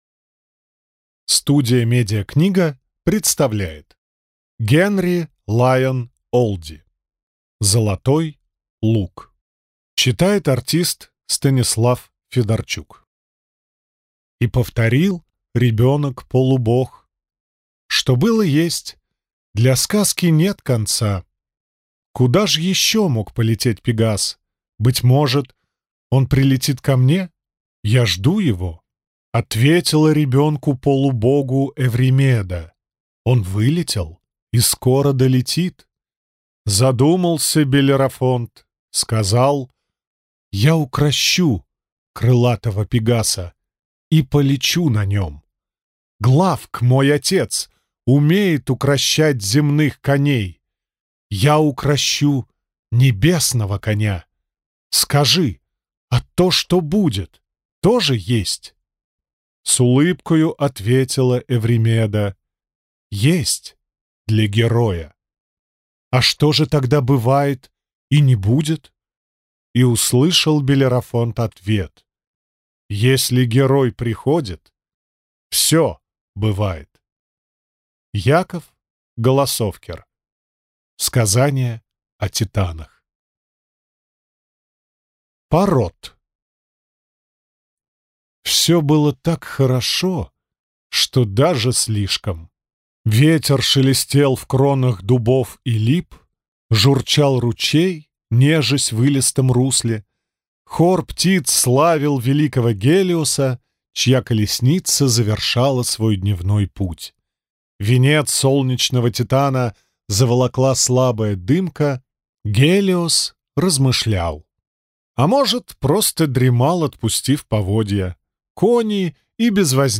Аудиокнига Золотой лук. Книга первая. Если герой приходит - купить, скачать и слушать онлайн | КнигоПоиск